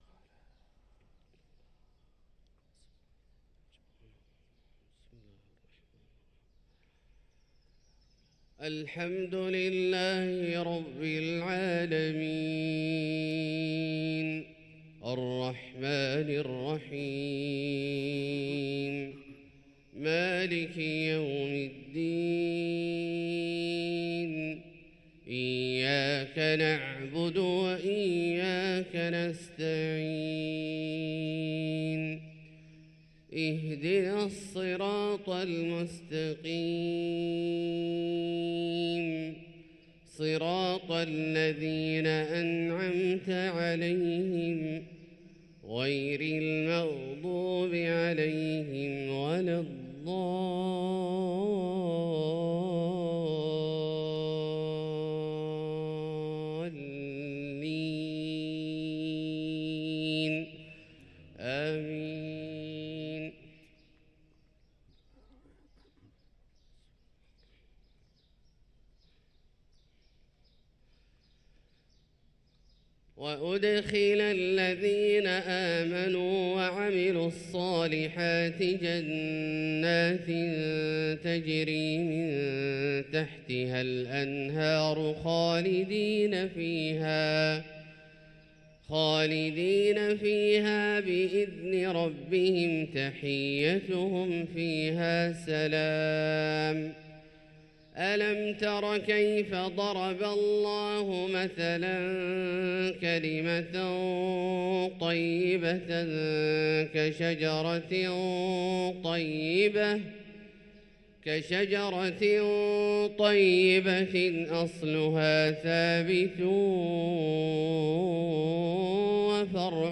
صلاة الفجر للقارئ عبدالله الجهني 23 ربيع الآخر 1445 هـ
تِلَاوَات الْحَرَمَيْن .